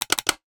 NOTIFICATION_Click_04_mono.wav